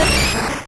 Cri d'Amonita dans Pokémon Rouge et Bleu.